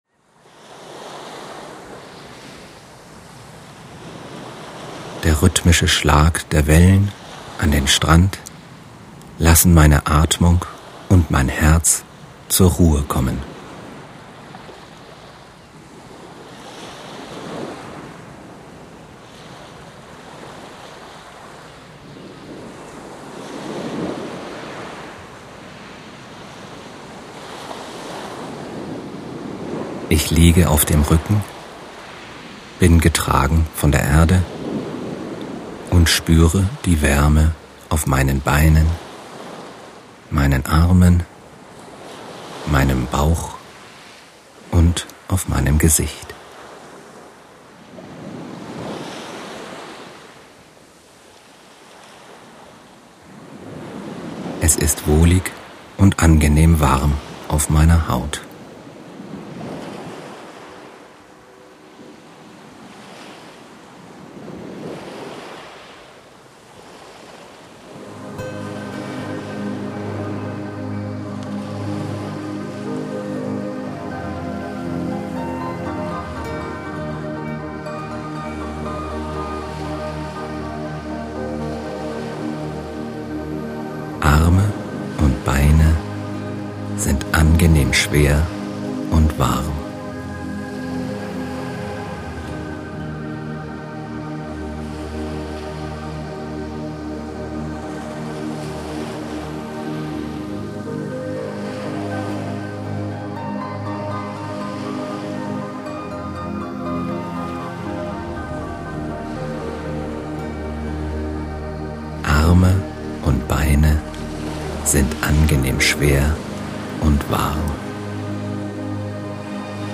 Der Klang des Meeres wird kombiniert mit einer sanften; wohlklingenden und eingängigen Entspannungsmusik. Die feine Abstimmung von Wort, Musik und Naturgeräuschen verstärkt die beruhigende Wirkung auf Ihren Geist und Körper. Das Meer und die Musik erklingen nach der Entspannungsübung lange weiter, halten Sie in Ihrem persönlichen Entspannungszustand und lassen Sie, wenn Sie nicht schon in Morpheus Armen ruhen, sanft und schwerelos einschlafen.
Audio Auszug / Vorschau Leichter Einschlafen mit Phantasiereise und Autosuggestion
syncsouls_leichter_einschlafen_fantasiereise_autosuggestion.mp3